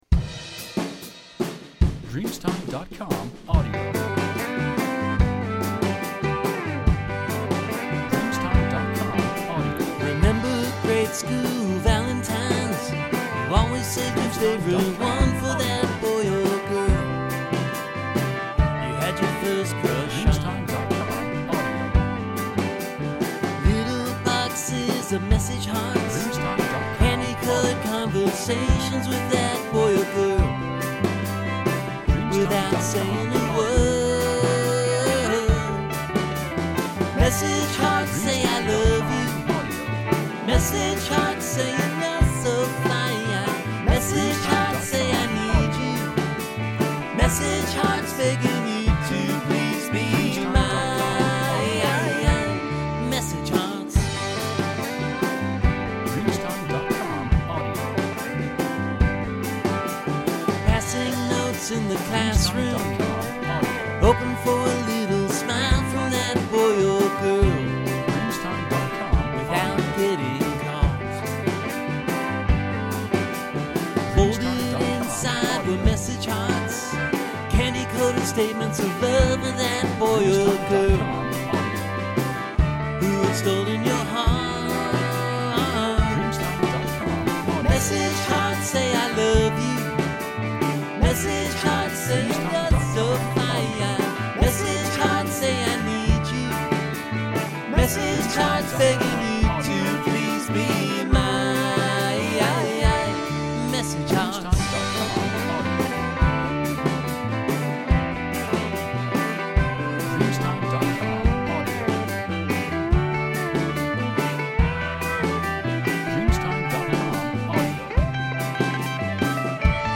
Jangle Pop Rock Song